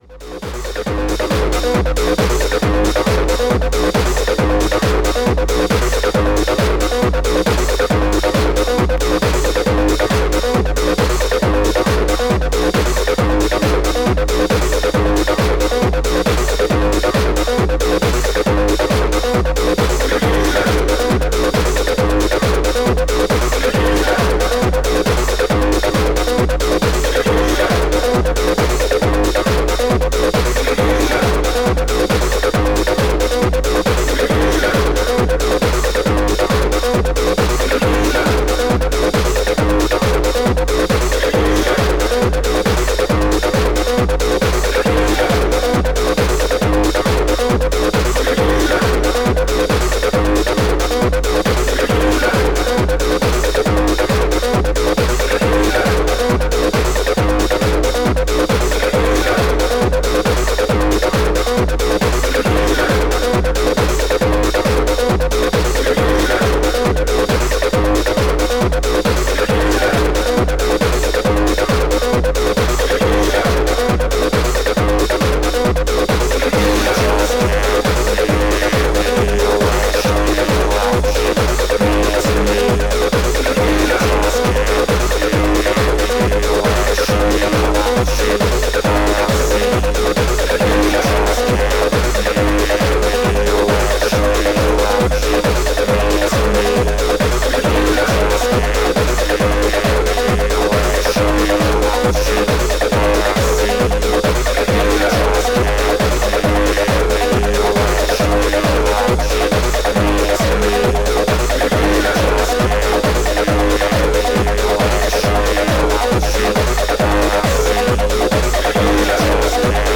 STYLE Acid / House / Hard House